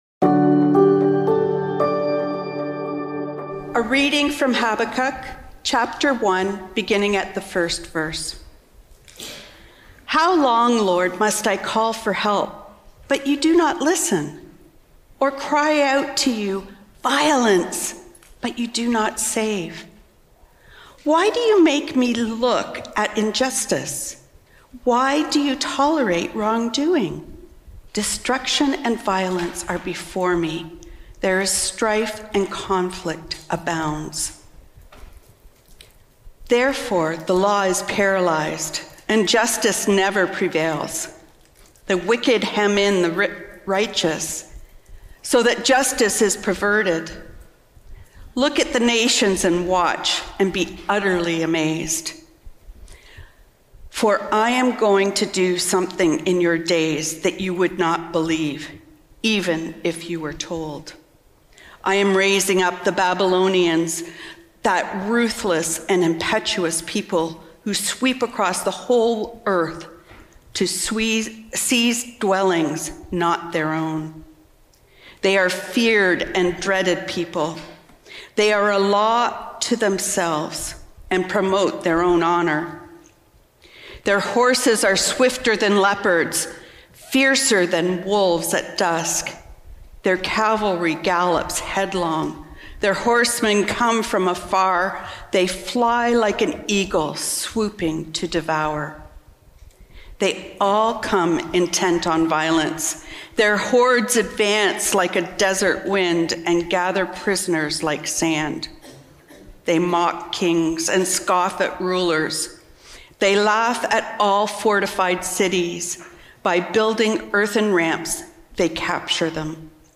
Trinity Streetsville - Dark Days | The Struggle Is Real | Trinity Sermons